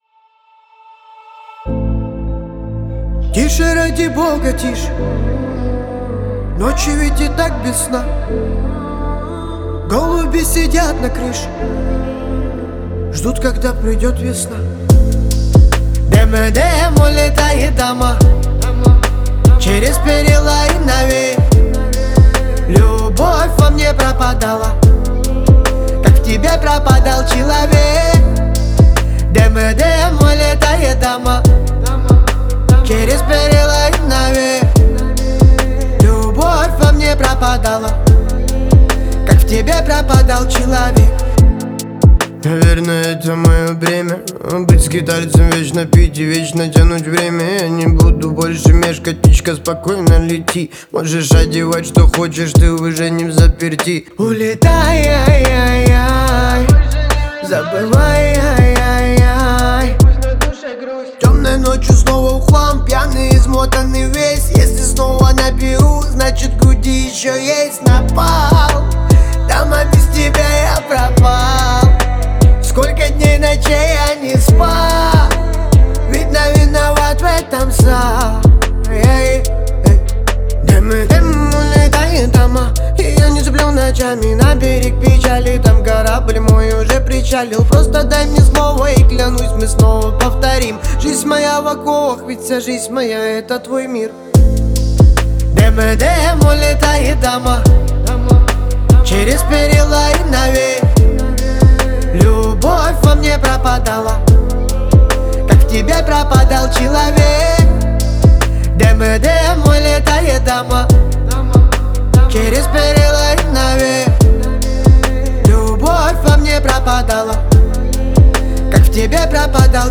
Жанр: Pop | Год: 2026